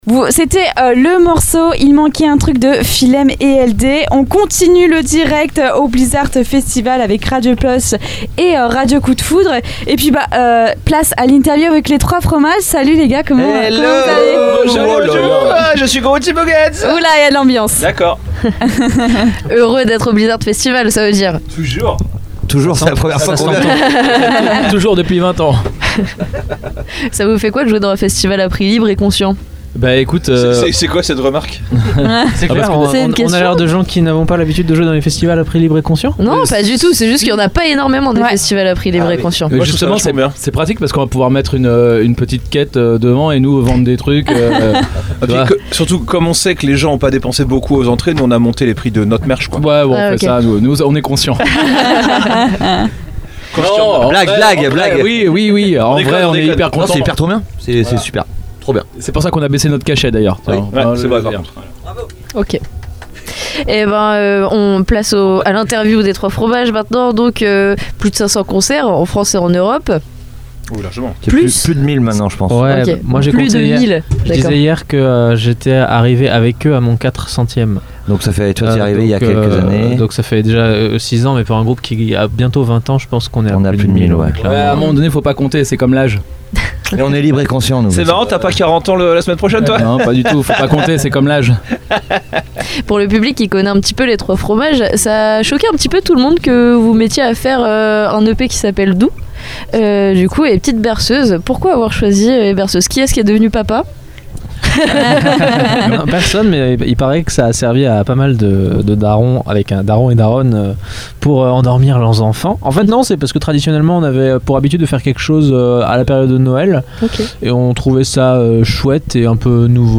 Juste après leur passage sur scène, ils ont pris place dans la Mystery Machine (studio radio aménagé dans une camionnette) pour une interview exclusive diffusée en direct sur Radio Pulse et Radio Coup de Foudre. Dans cette rencontre pleine d’humour et d’énergie, le groupe revient sur son parcours atypique, ses influences entre rock, punk et second degré, ainsi que sur l’élaboration de ses morceaux aussi efficaces que délirants. Ils partagent avec nous les dessous de leur dernier album, leurs meilleurs souvenirs de scène et leur vision du live comme terrain de jeu et de fête.